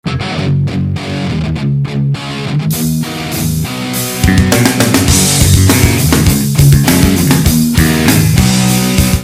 Self Recorded